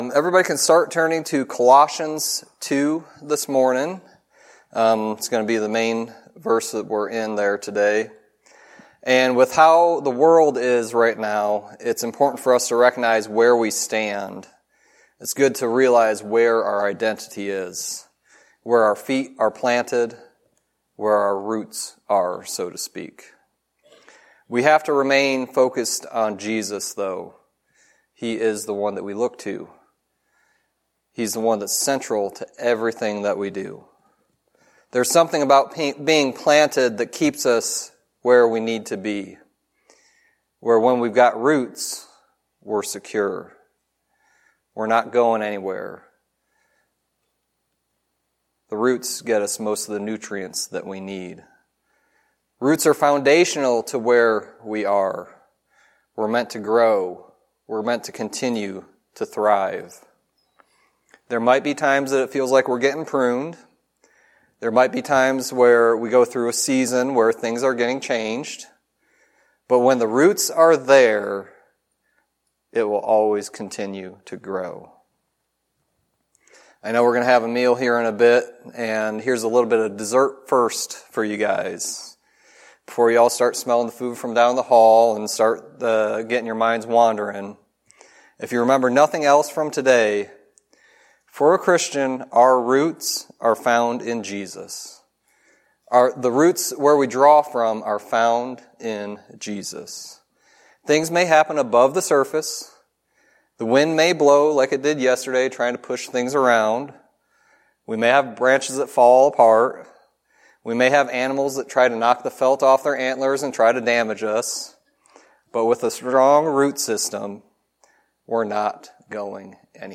Sermon messages available online.
Colossians 2:4-10 Service Type: Sunday Teaching Roots are important for anything.